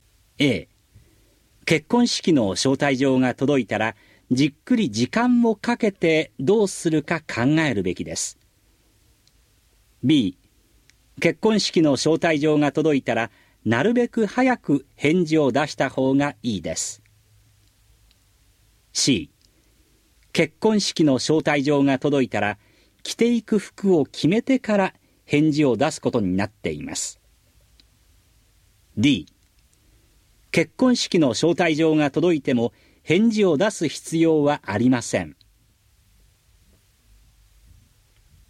Conversation 05